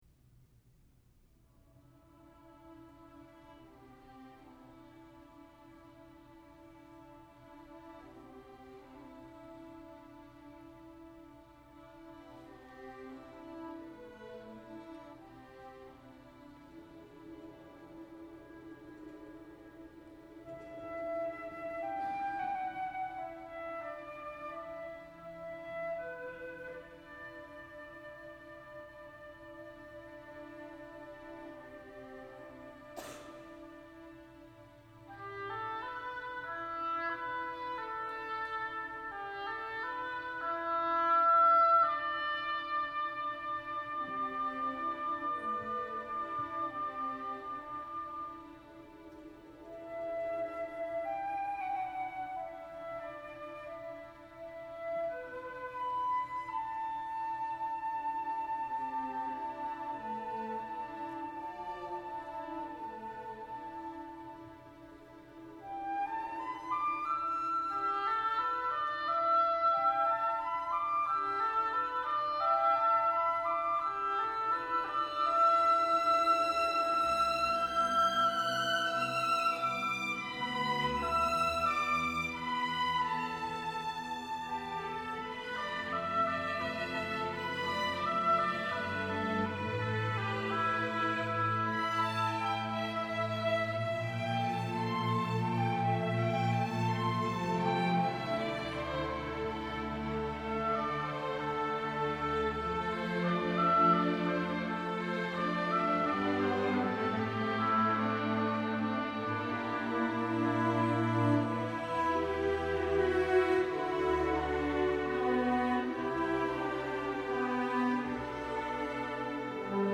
for Orchestra (1994)